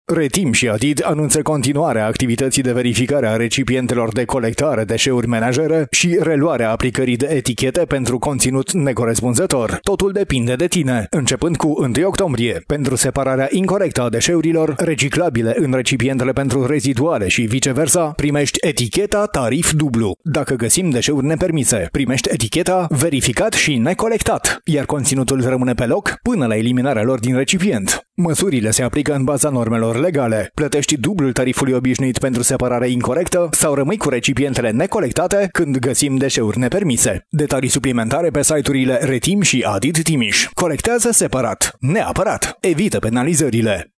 spot-radio-Eticheta.mp3